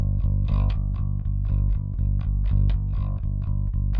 Live Bass Guitar Loops " Bass loop 120 bpm rock eights g
描述：小套的贝斯循环典型的摇滚八音，速度为120 bpm不同的音符（在文件名后面）。循环完美。有压缩器的线型低音信号。指点迷津。
Tag: 手指 摇滚 现场 吉他 basa-吉他 120BPM 八分 低音 恒定